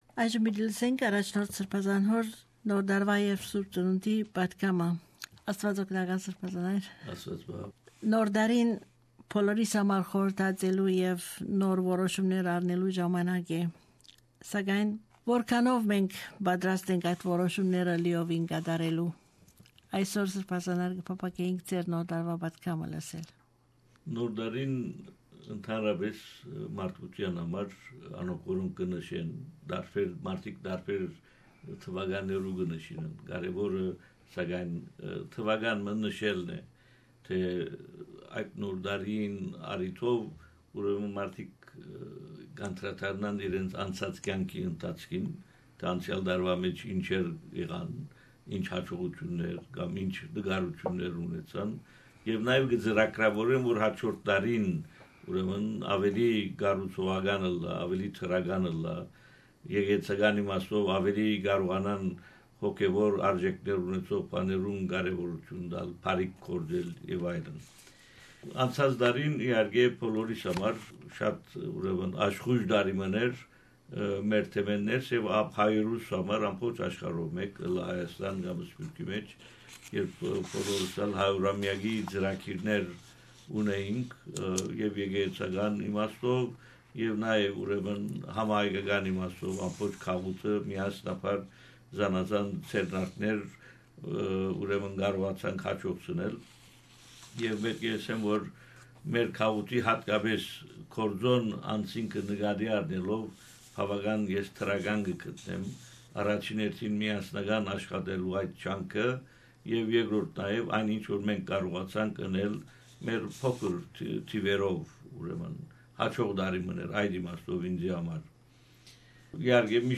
New Year message by His Grace, Bishop Haygazoun Najarian Primate of Armenians in Australia and New ZSealand.